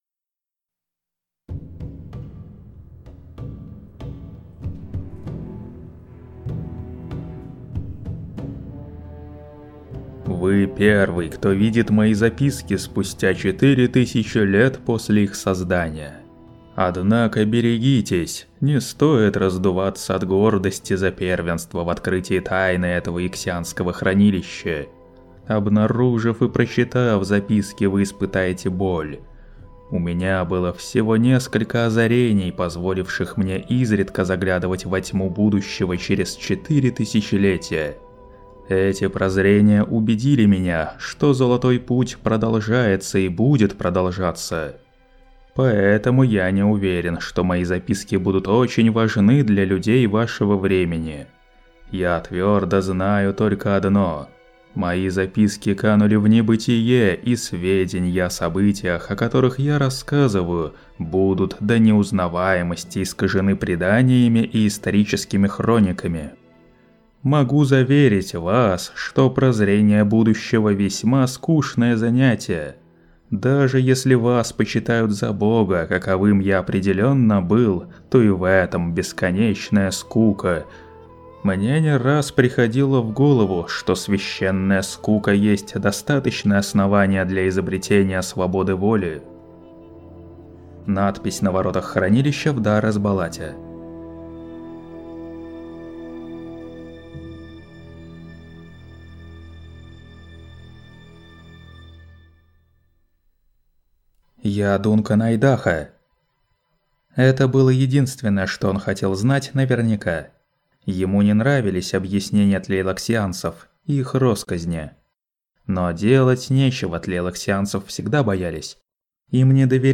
Аудиокнига Бог-Император Дюны. Часть 5.
На этой странице выложена часть № 5 аудиокниги «Бог-Император Дюны» по одноименному произведению Фрэнка Герберта.